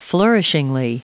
Prononciation du mot flourishingly en anglais (fichier audio)
Prononciation du mot : flourishingly